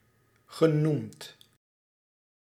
Ääntäminen
IPA : /sɛːd/